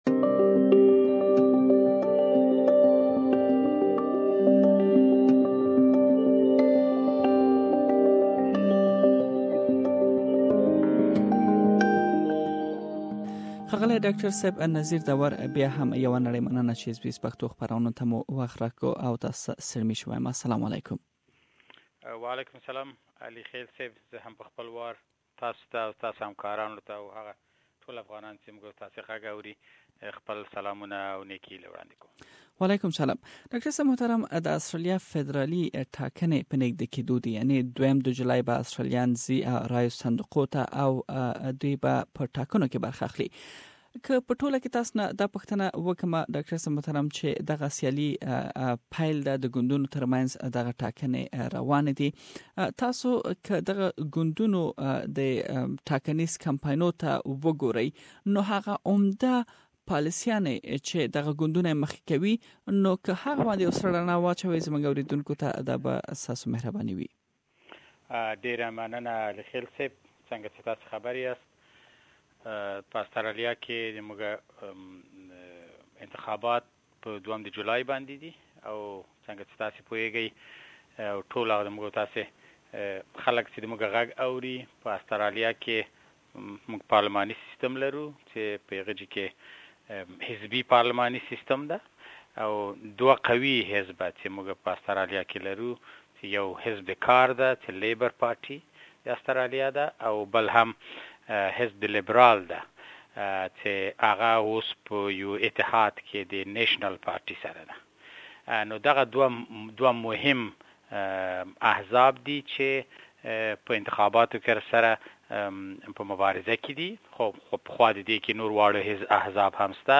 We have looked at the policies of the two major parties and have also highlighted why it is important that you should take part in election. We've interviewed Sydney based lawyer and political analyst